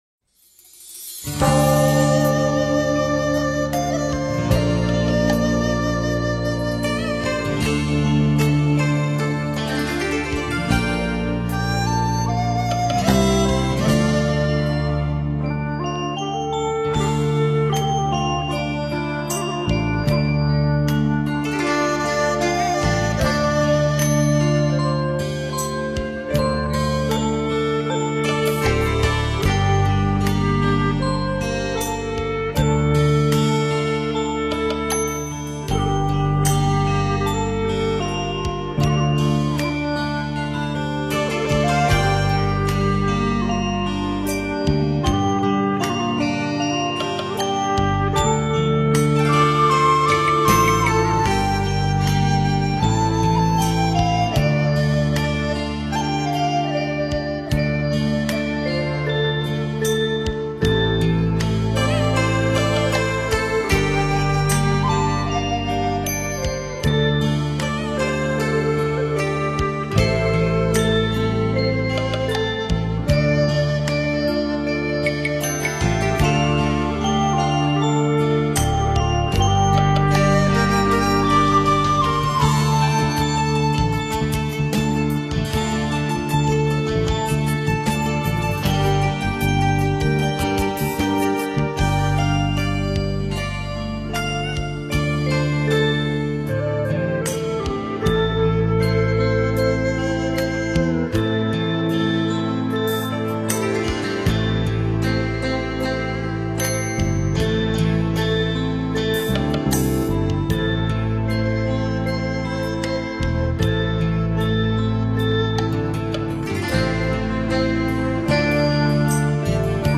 佛教音乐-心经--佛歌洞萧纯音乐 菩萨 冥想 佛教音乐-心经--佛歌洞萧纯音乐 菩萨 点我： 标签: 佛音 冥想 佛教音乐 返回列表 上一篇： 禅茶人生(纯音乐)--佛教音乐 下一篇： Crystal Fields--水晶原野《唵（圣音）》 相关文章 貧僧有話29說：我对问题的回答--释星云 貧僧有話29說：我对问题的回答--释星云...